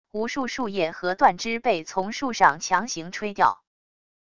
无数树叶和断枝被从树上强行吹掉wav音频